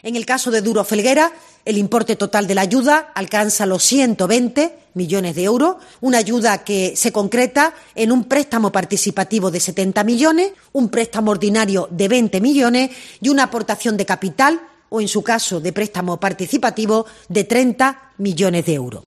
María Jesús Montero explica el reparto de los 120 millones de euros para rescatar a Duro Felguera
Así lo ha anunciado en la rueda de prensa posterior al Consejo de Ministros la portavoz del Gobierno y ministra de Hacienda, María Jesús Montero, quien ha apuntado que este apoyo temporal estaba condicionado a la reestructuración de la deuda sindicada, así como a la incorporación de dos consejeros designados por el consejo gestor del fondo.